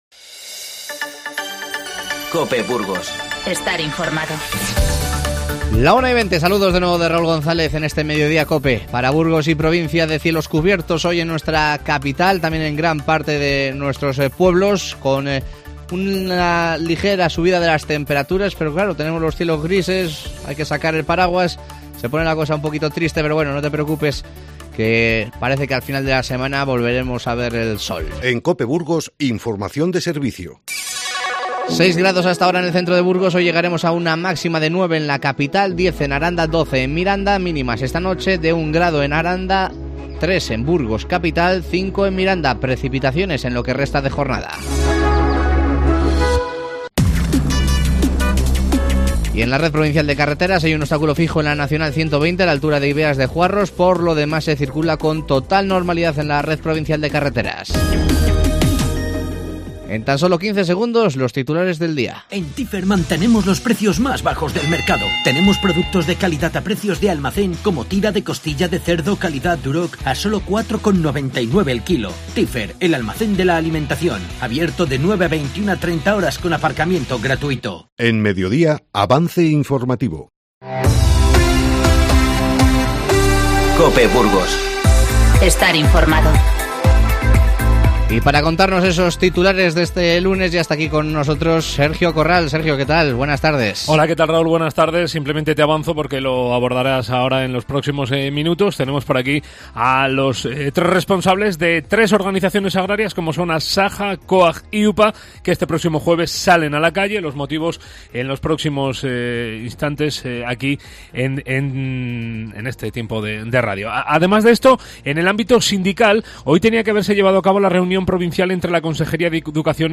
Te avanzamos las principales noticias del día y charlamos con los representantes de las 3 organizaciones agrarias más importantes de Burgos: Asaja, UPA y COAG, sobre la manifestación que realizarán el próximo 30 de enero.